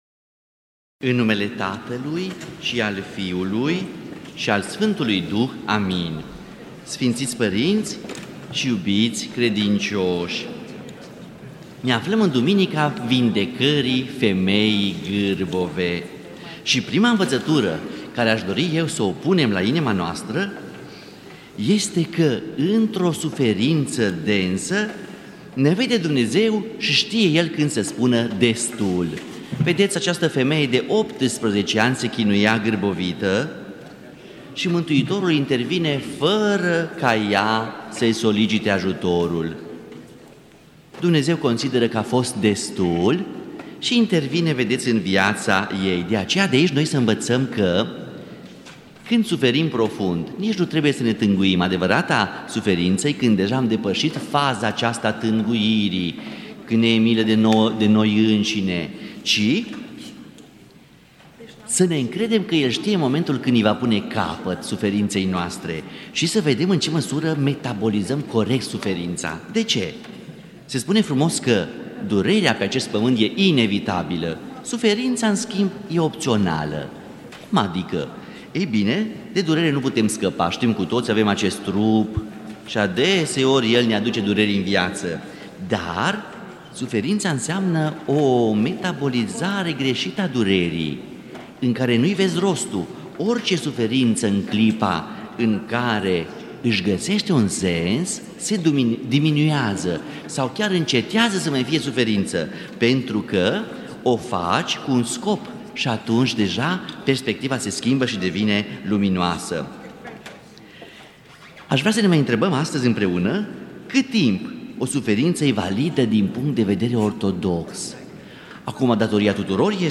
Predică la Duminica a 27-a după Rusalii (a tămăduirii femeii gârbove)